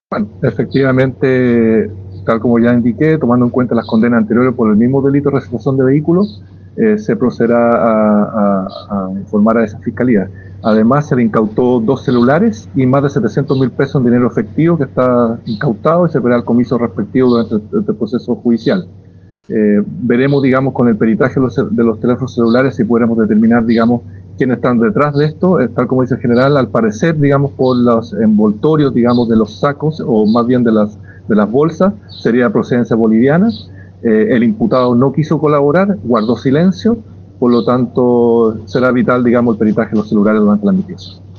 Escucha al Fiscal Regional de Coquimbo, Patricio Cooper
Fiscal.mp3